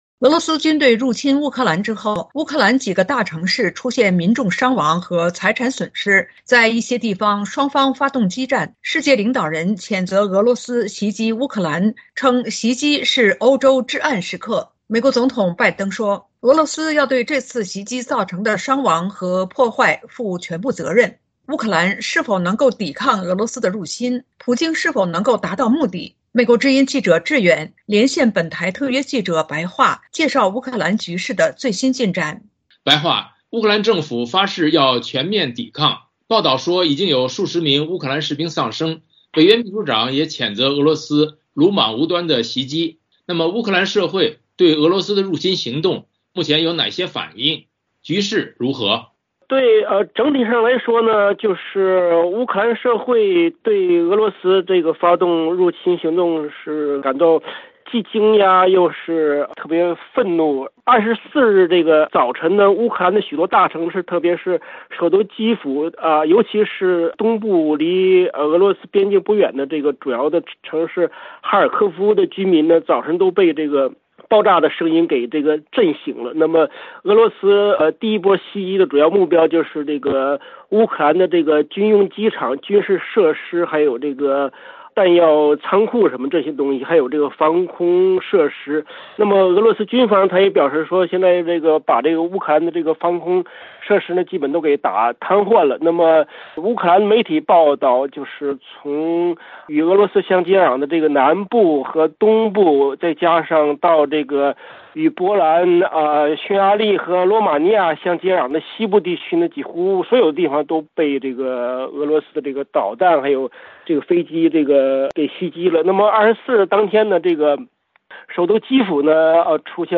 VOA连线：记者连线：俄罗斯三面袭击乌克兰 东欧国家紧张